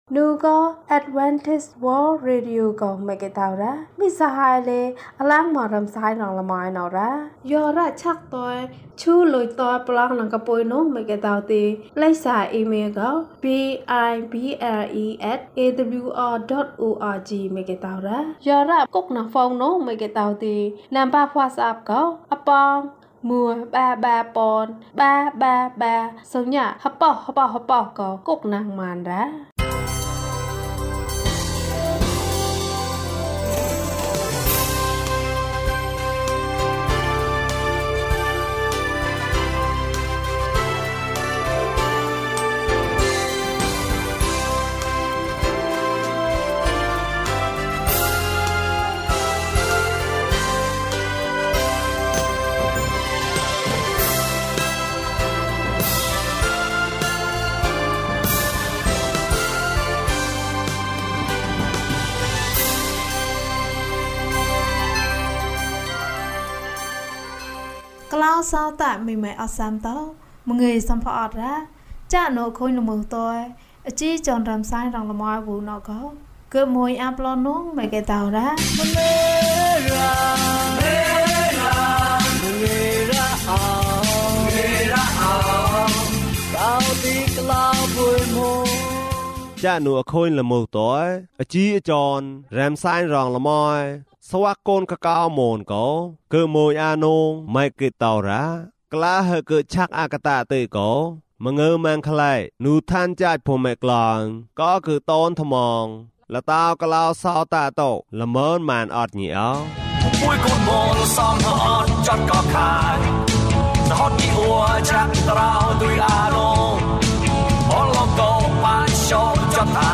ခရစ်တော်ထံသို့ ခြေလှမ်း။၀၇ ကျန်းမာခြင်းအကြောင်းအရာ။ ဓမ္မသီချင်း။ တရားဒေသနာ။